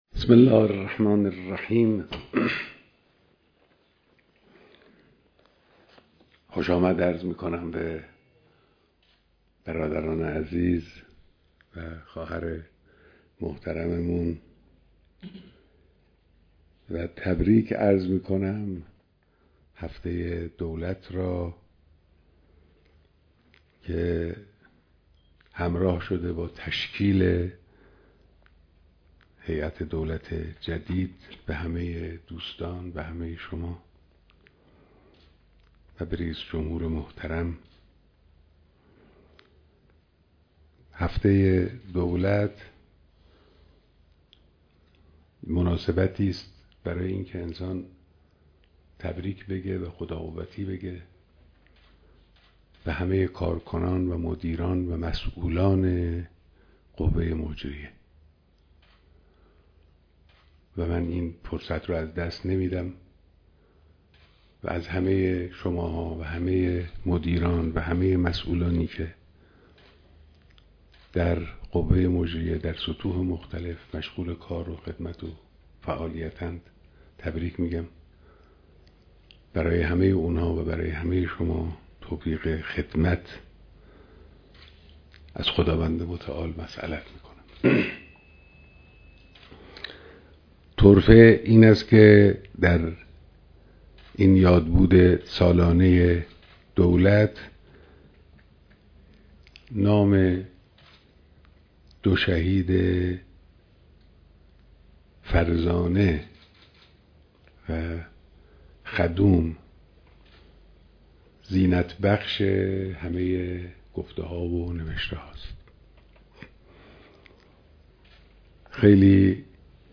بیانات در دیدار رئیس جمهور و اعضای هیئت دولت